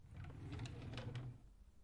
22 打开抽屉( 安培+)
描述：一个人打开木抽屉的声音，这个有更多的音量
标签： 开放 木材 抽屉
声道立体声